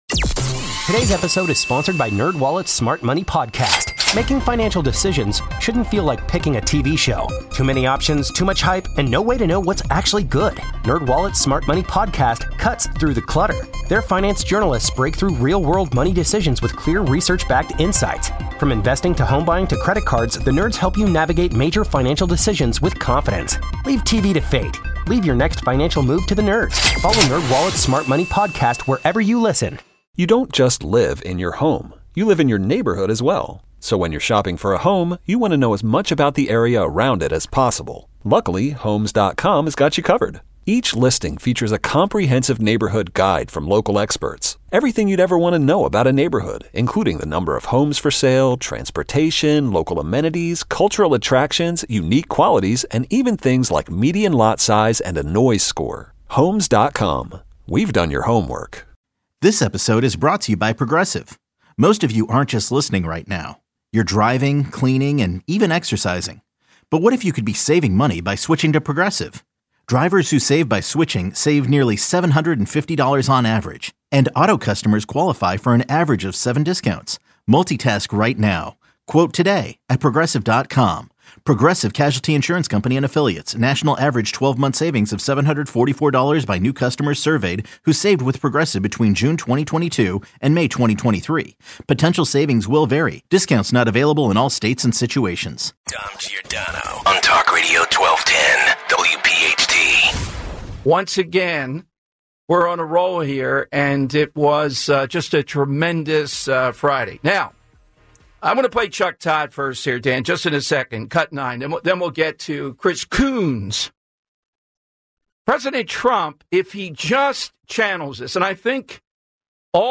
Full Hour